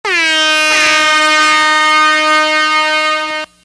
DJ Air Horn
Category: Radio   Right: Personal